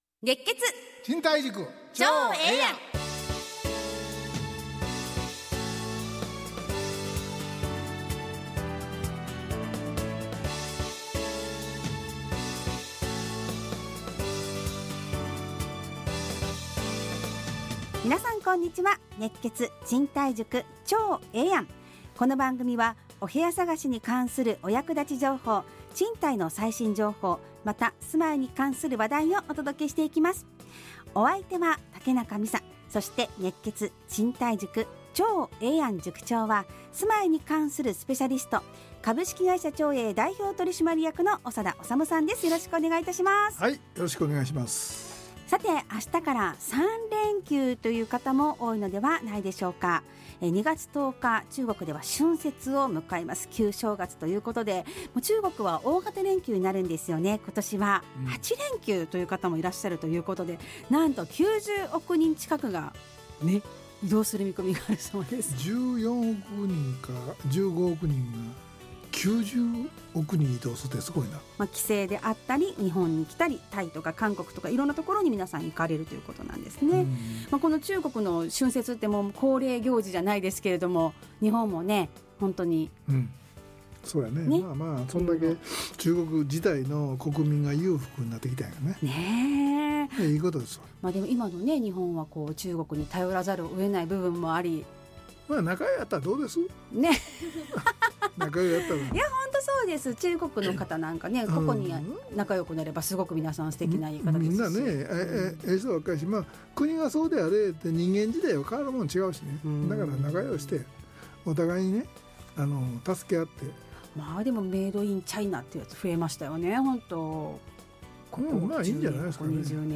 ラジオ放送 2024-02-09 熱血！賃貸塾ちょうええやん【2024.2.9放送】 オープニング：明日から3連休、中国春節 延べ90億人が移動？